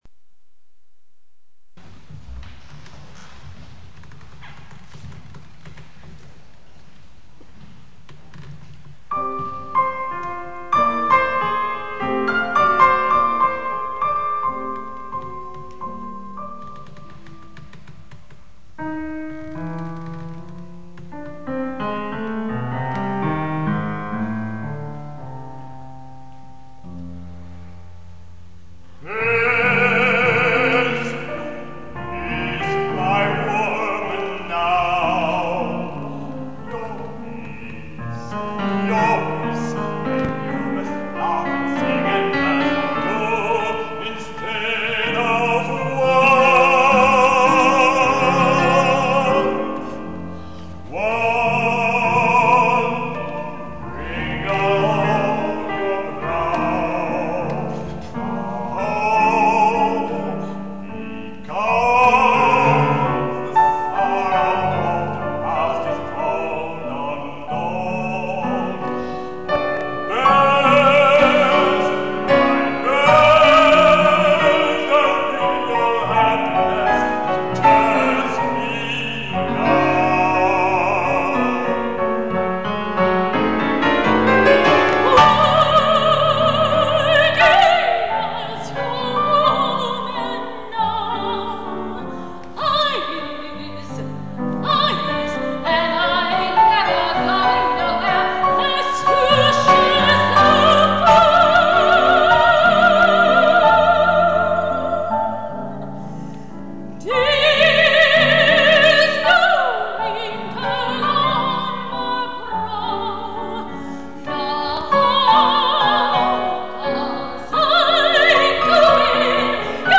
soprano
baryton
au piano